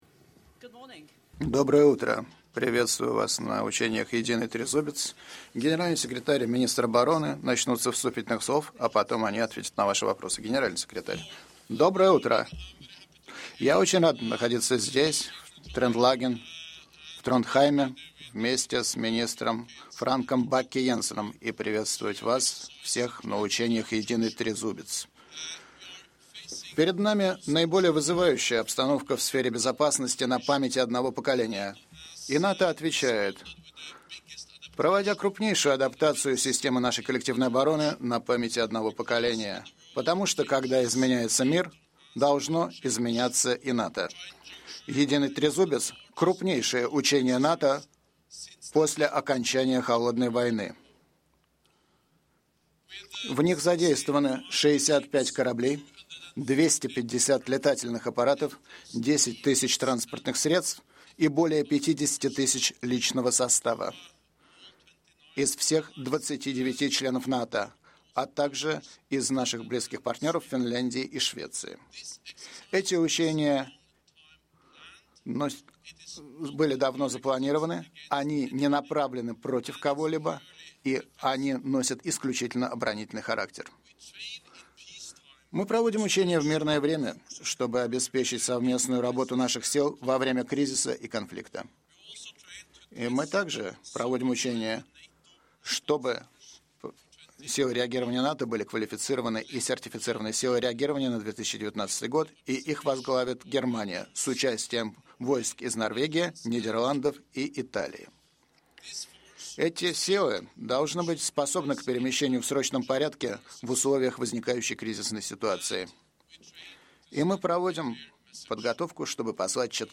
Joint press conference
with NATO Secretary General Jens Stoltenberg and the Minister of Defence of Norway, Frank Bakke-Jensen at the Trident Juncture 2018 distinguished visitors’ day